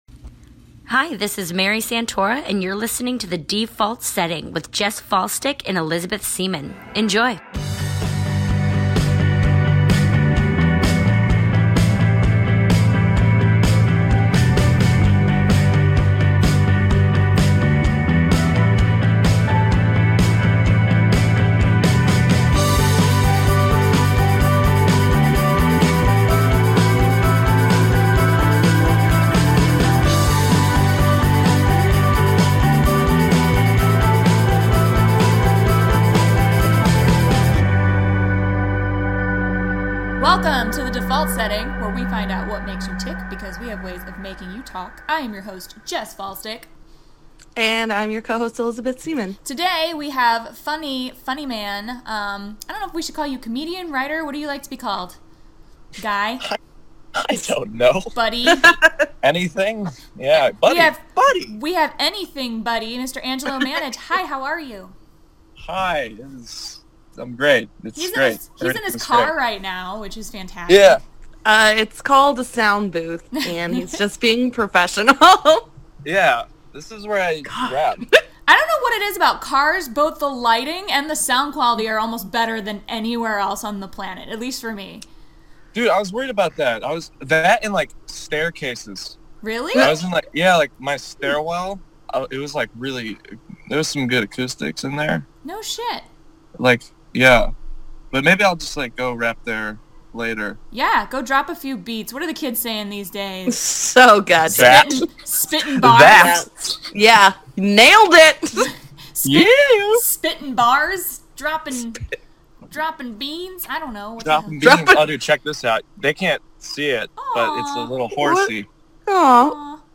OCD isn't just a fun way to describe being annoyingly meticulous; it can actually be quite the contrary. The three of us all describe our own past run-ins with the disorder and how we've coped/ignored it.